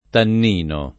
tannino [ tann & no ]